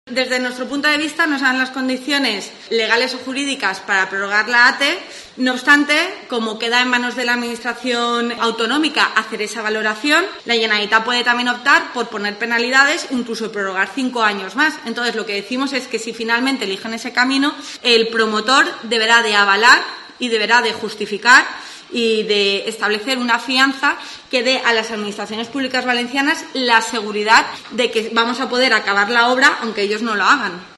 AUDIO. Sandra Gómez, contundente con la ATE